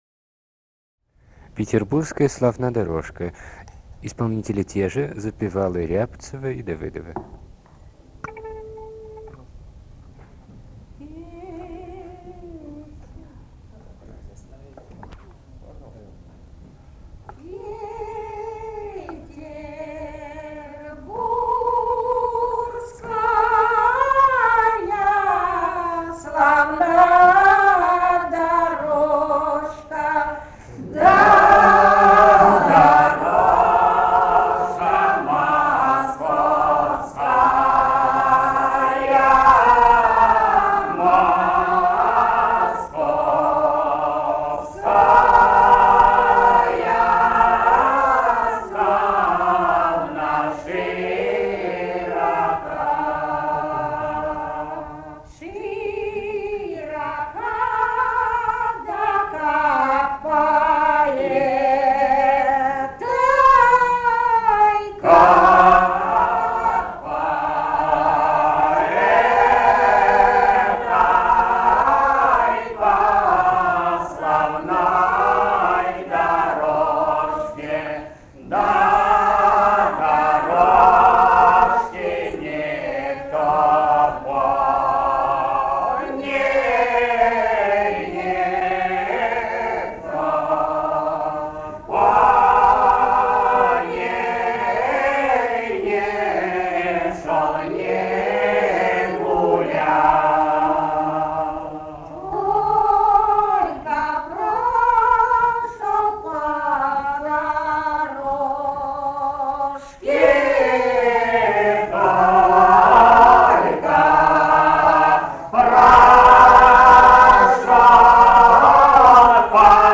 Русские народные песни Владимирской области 13. Петербургская славна дорожка (рекрутская протяжная) г. Ковров Ковровского района Владимирской области.